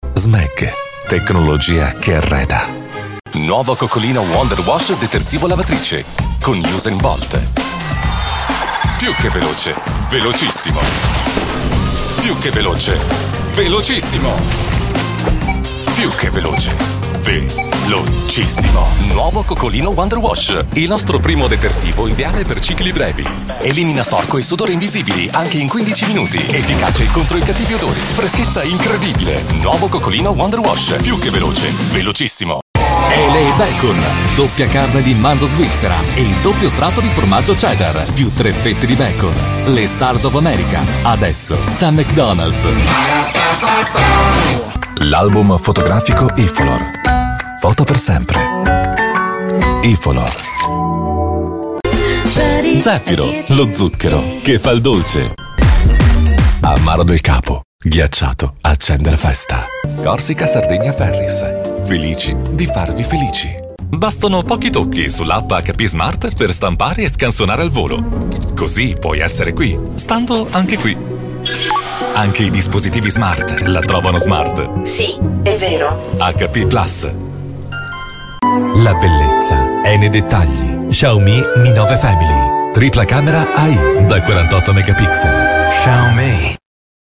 Male
My voice is young and versatile. Warm for narrations, dynamic for commercials, smooth and professional for presentations.
Television Spots